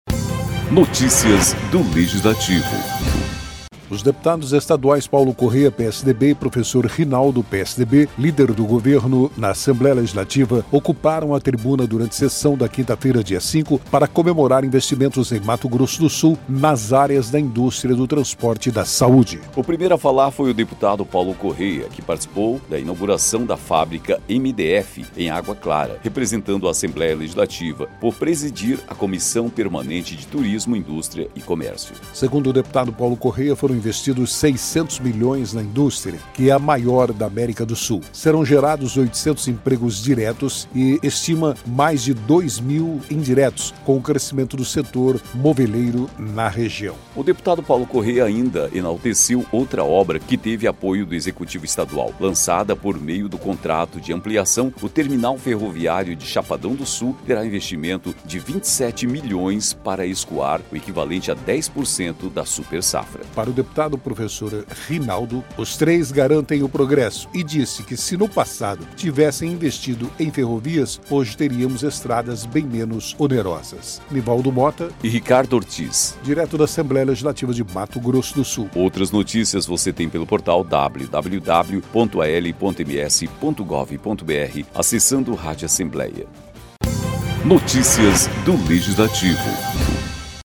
Os deputados estaduais Paulo Corrêa (PSDB) e Professor Rinaldo (PSDB) – líder do Governo no Assembleia Legislativa – ocuparam a tribuna durante sessão desta quinta-feira (5) para comemorar investimentos em Mato Grosso do Sul nas áreas da indústria, do transporte e da saúde.